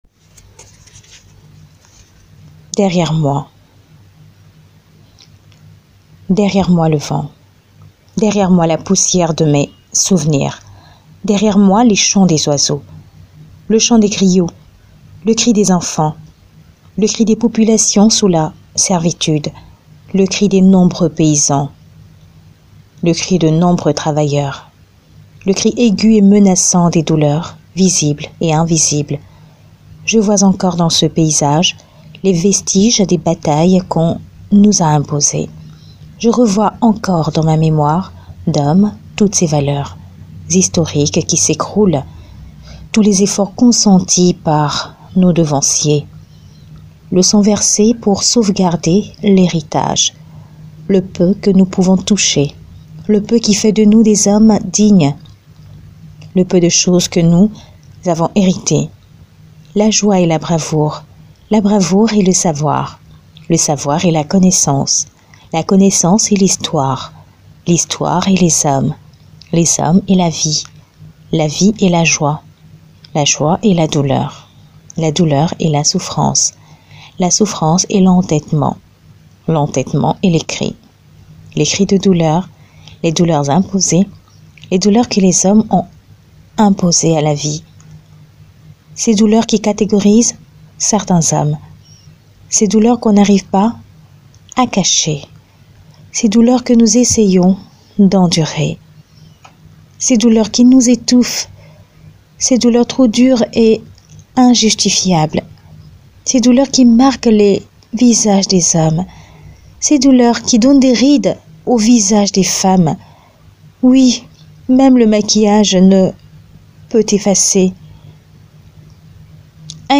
POEME : Derrière Moi